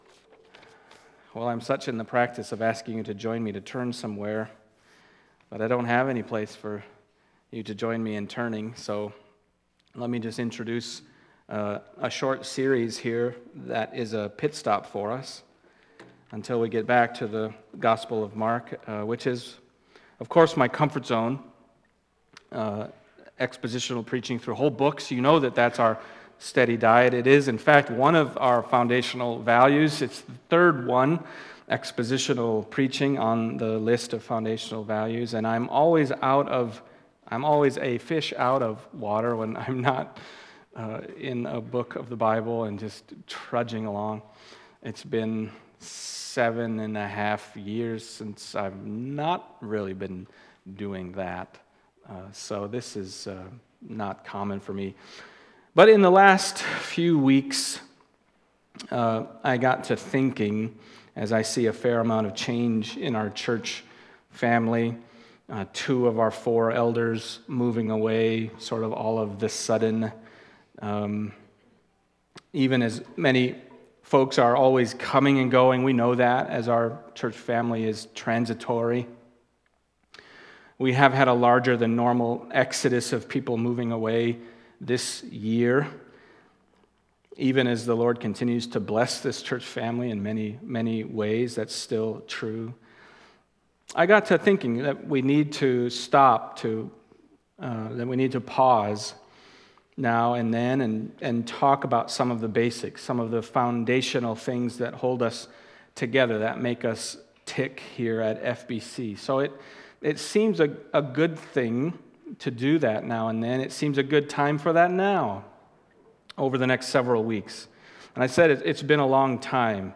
Foundational Values Service Type: Sunday Morning 1. God-Centeredness